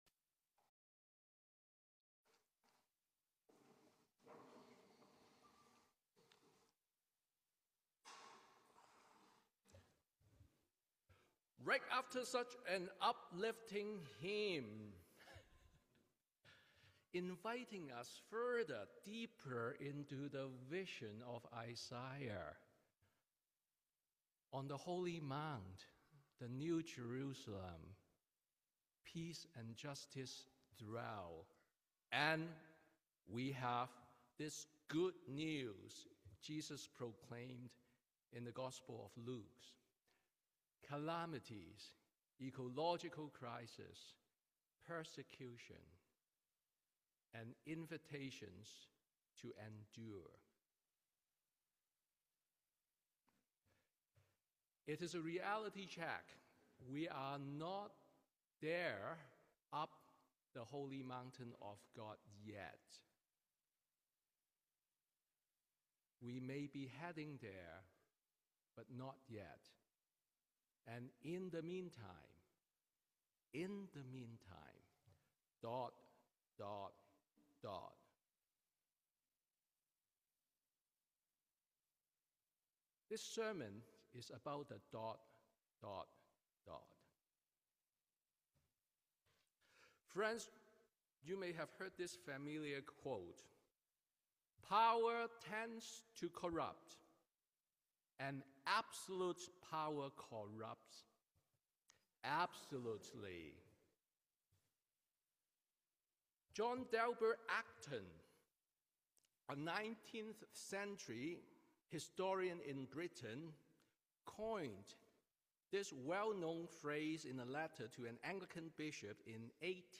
Sermon on the Twenty-third Sunday after Pentecost